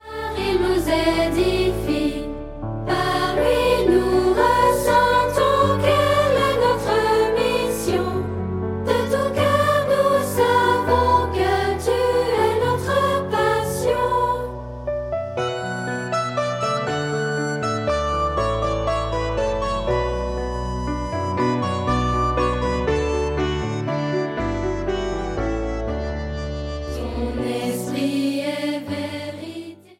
Comédie musicale